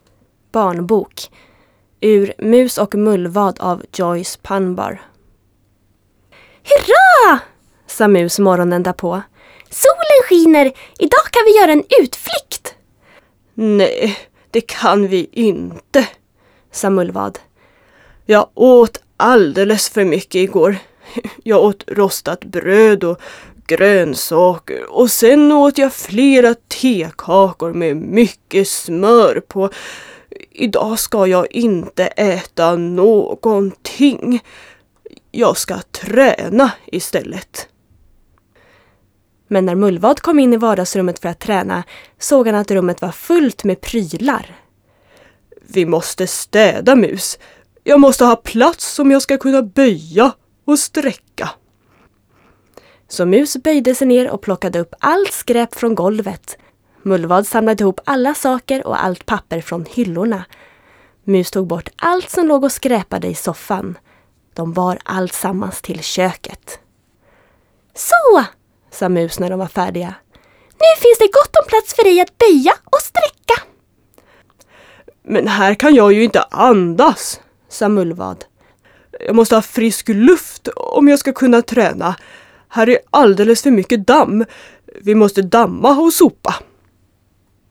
voice over demo (swedish)
children's book (in swedish)